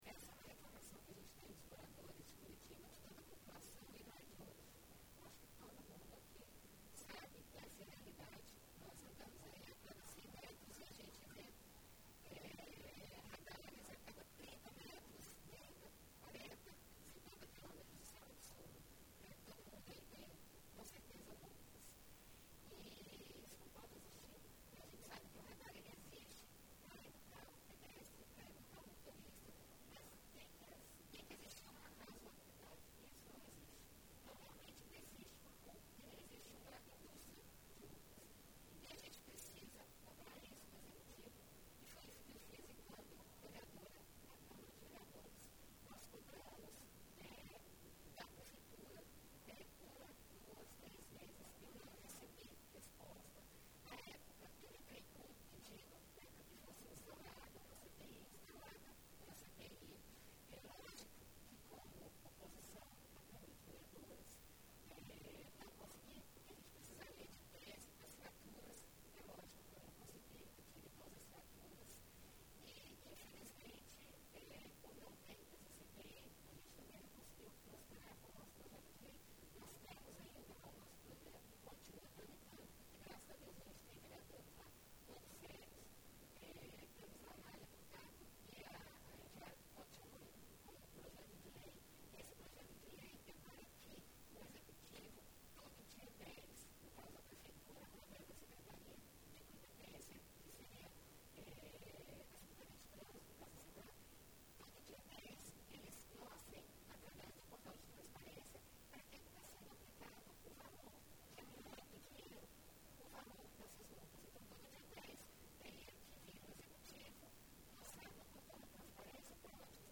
A deputada estadual Flávia Francischini (União Brasil) subiu à tribuna do Plenário nesta quarta-feira (26) para acabar com a “Indústria de multas” que existe em Curitiba.